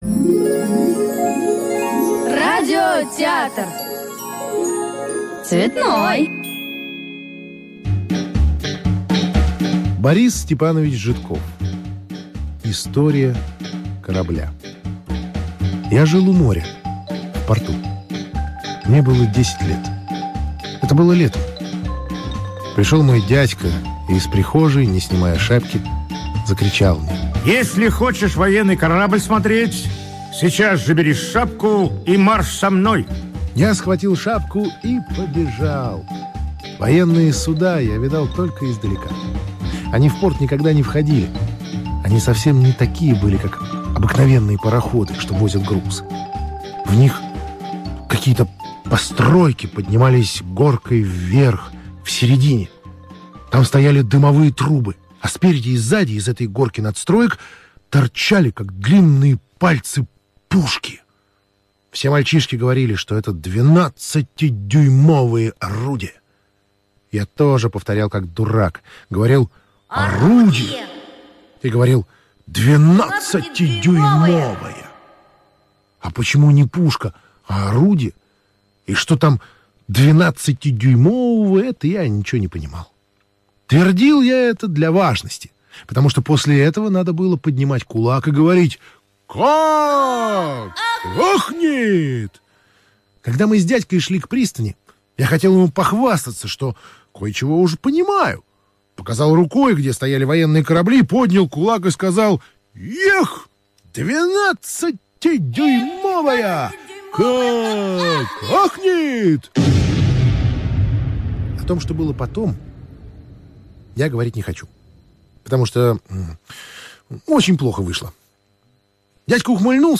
История корабля - аудио рассказ Житкова Б.С. Однажды десятилетнему мальчику посчастливилось побывать с дядькой на военном корабле.